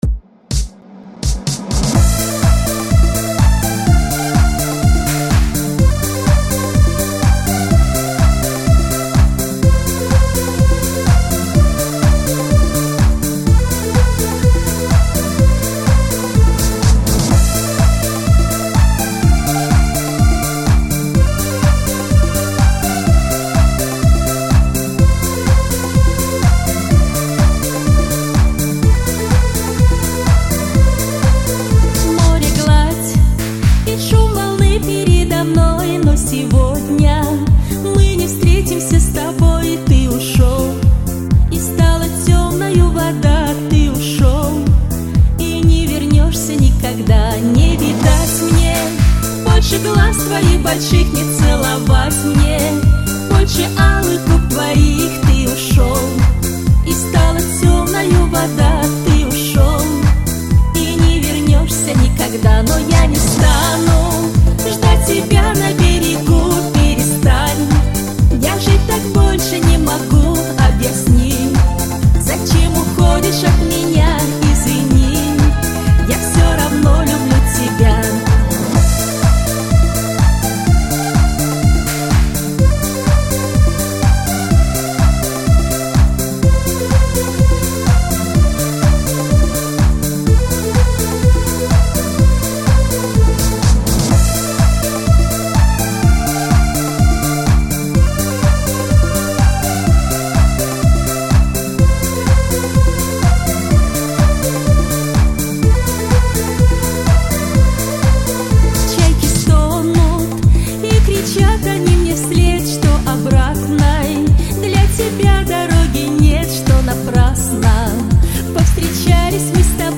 У этой старенькой дворовой песни много вариантов исполнения.